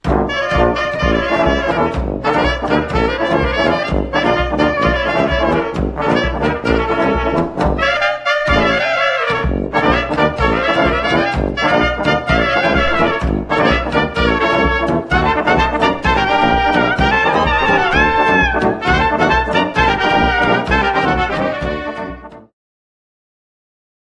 trąbka trumpet, sakshorn altowy alto saxhornes
kontrabas double bass, gitara guitar